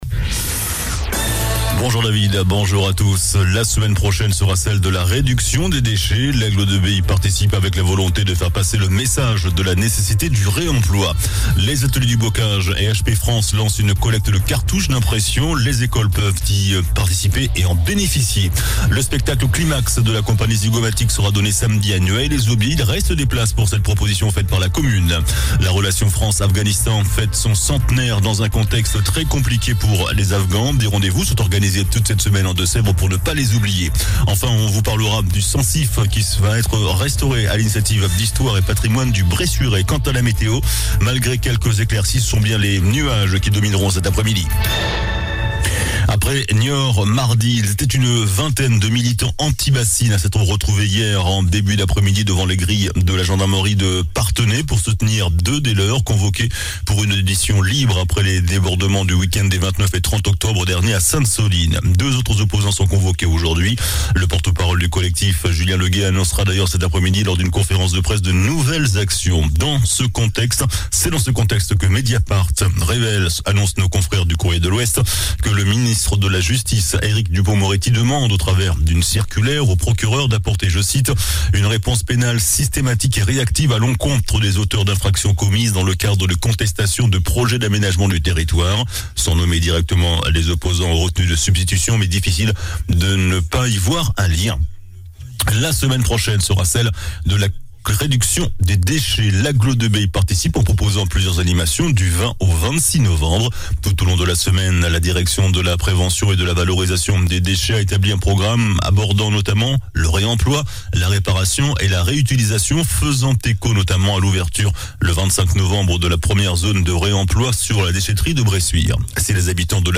JOURNAL DU JEUDI 17 NOVEMBRE ( MIDI )